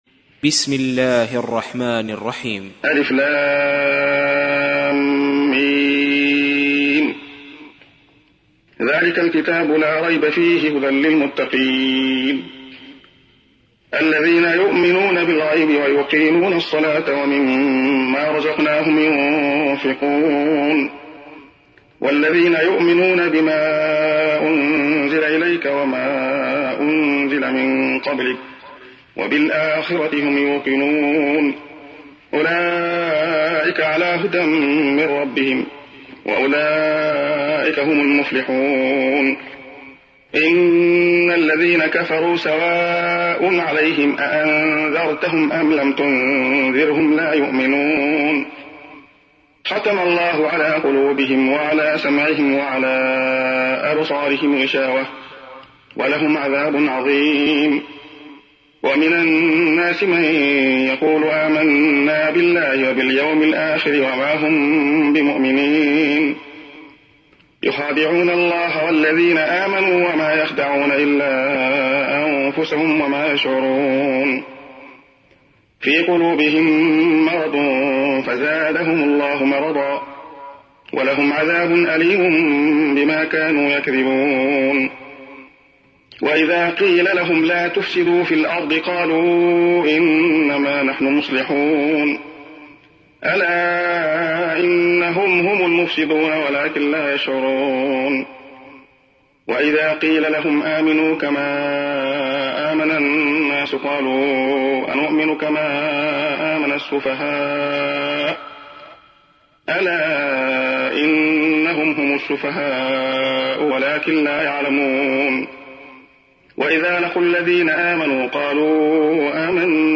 تحميل : 2. سورة البقرة / القارئ عبد الله خياط / القرآن الكريم / موقع يا حسين